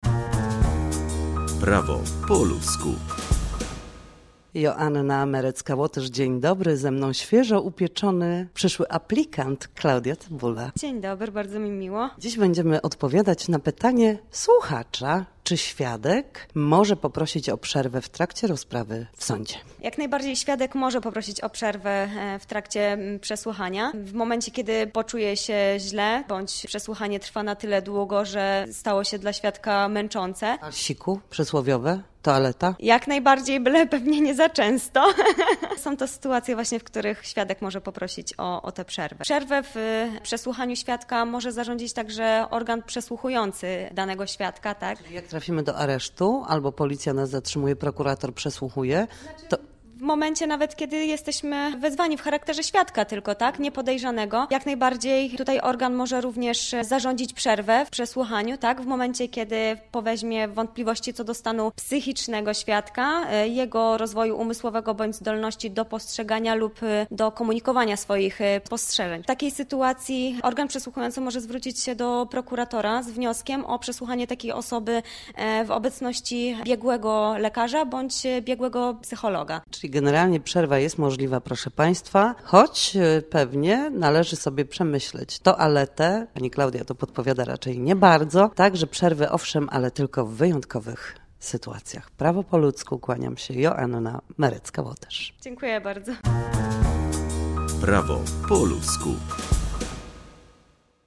W każdy piątek o godzinie 7:20 na antenie Studia Słupsk przybliżamy meandry prawa.
Nasi goście, prawnicy, odpowiadają na pytania, dotyczące zachowania w budynku sądu, podstawowych zagadnień prawniczych czy pobytu na sali sądowej.